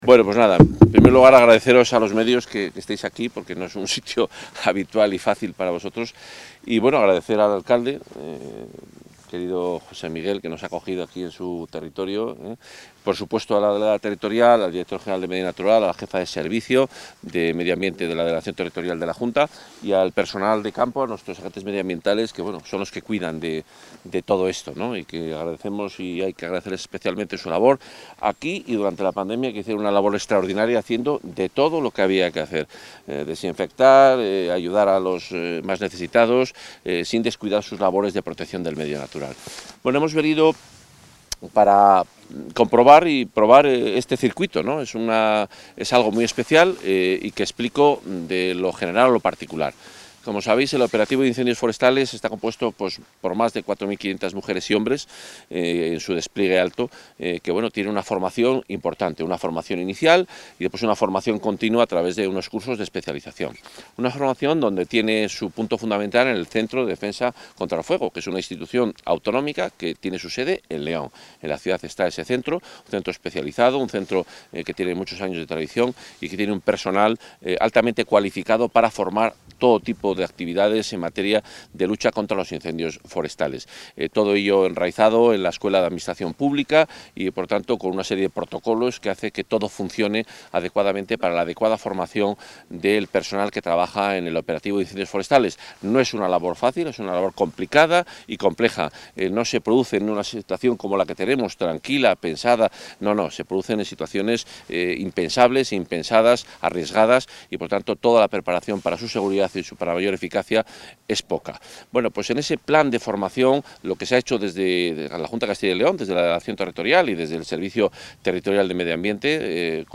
Audio consejero en funciones.